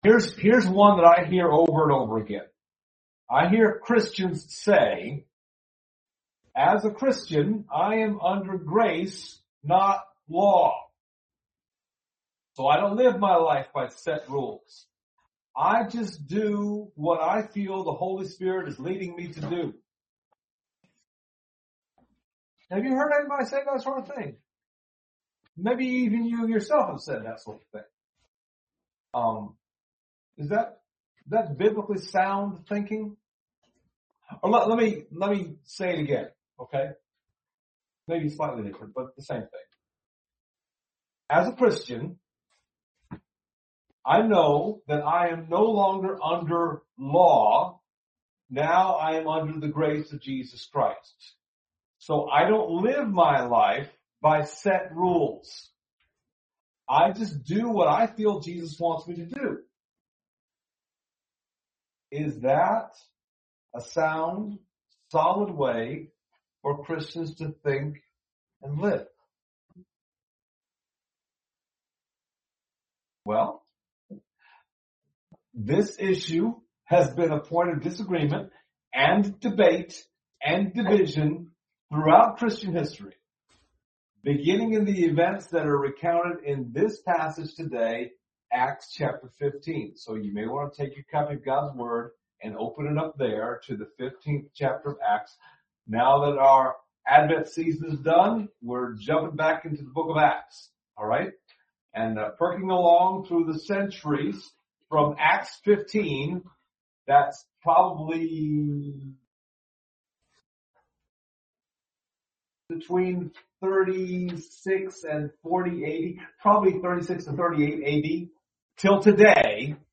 Passage: Acts 15:1-6 Service Type: Sunday Morning